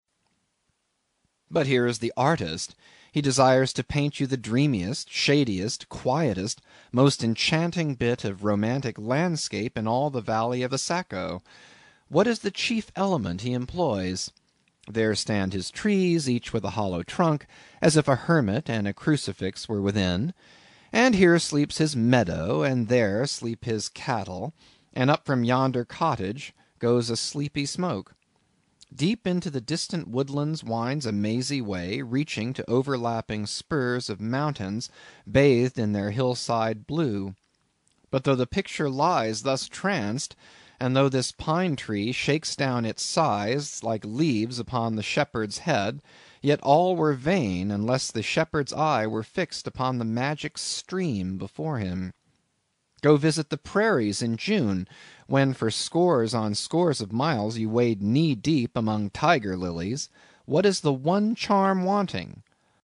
英语听书《白鲸记》第170期 听力文件下载—在线英语听力室